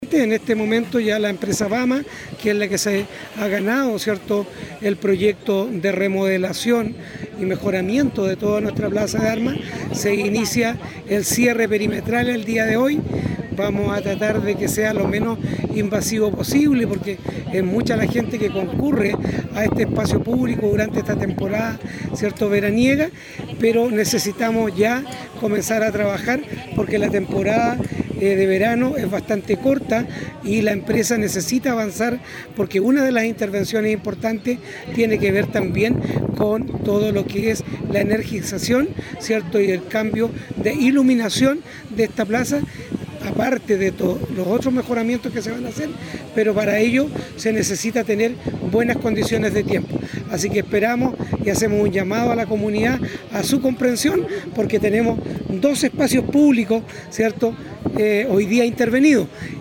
En el primero de los trabajos, que tiene que ver con la plaza de armas, desde la semana pasada que se inició el cercado del lugar, como lo confirmó el alcalde Carlos Gómez.